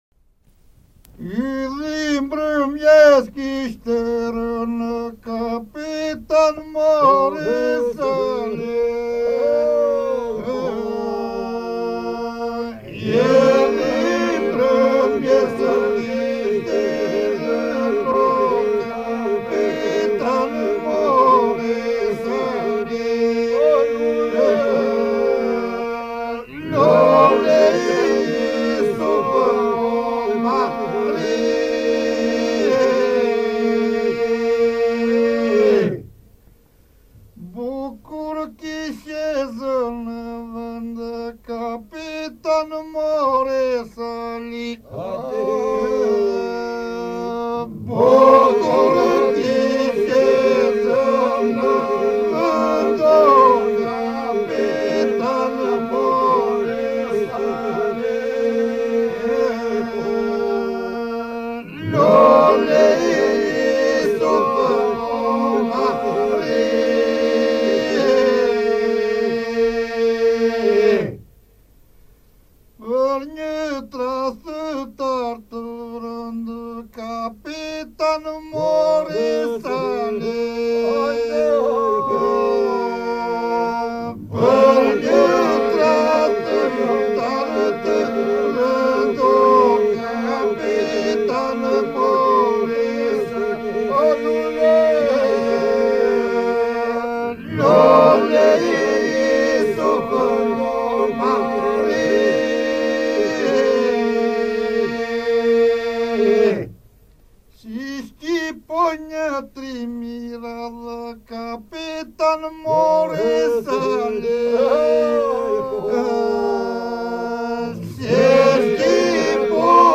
Grup popullor iso polifonik më i shquar i qytetit muze të Gjirokastrës i viteve 1940-1980.
Database of Albanian Folk Iso-Polyphony